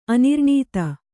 ♪ anirṇīta